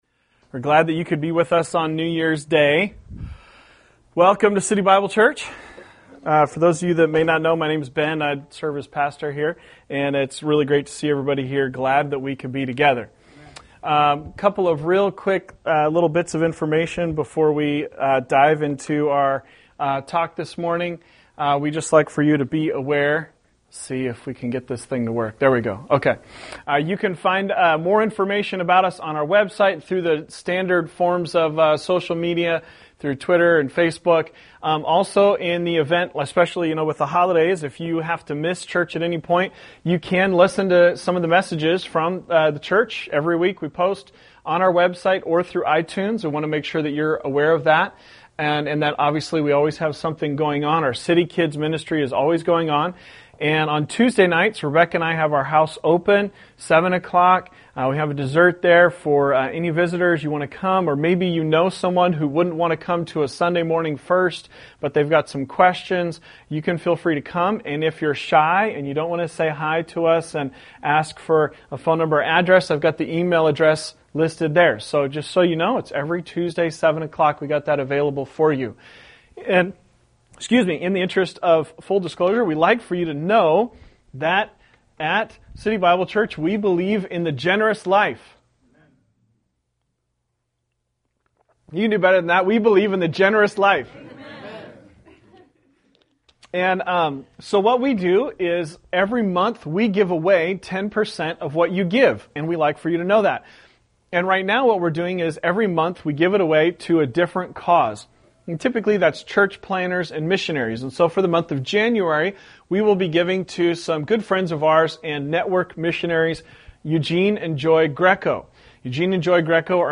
On our New Years Day service we look at Daniel and the Lions Den.